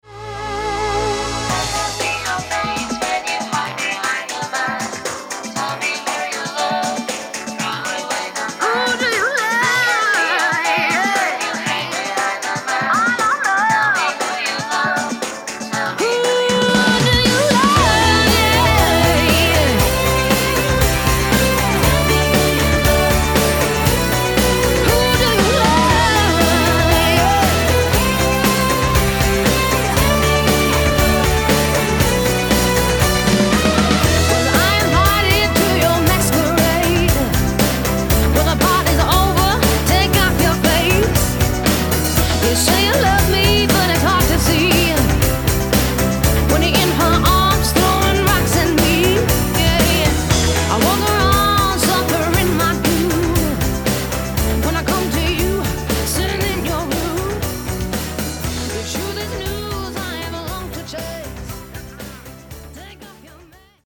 CLASSIC SONG COVERS
Backing Vocals…
Fender ’63 Precision Bass